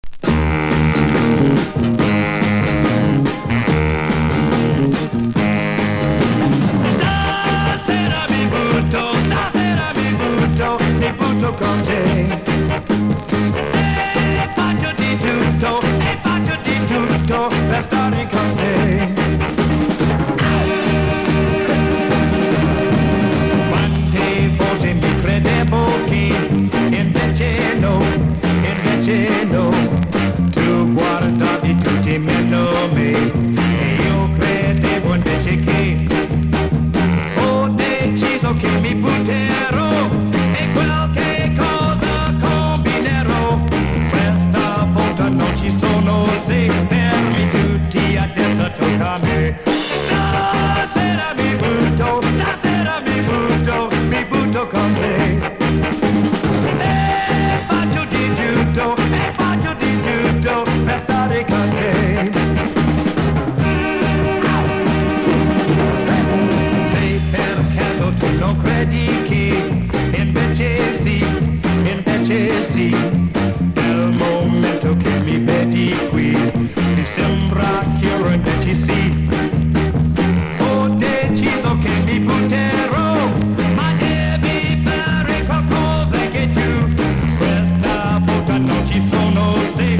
形式 : 7inch / 型番 : / 原産国 : ITA
所によりノイズありますが、リスニング用としては問題く、中古盤として標準的なコンディション。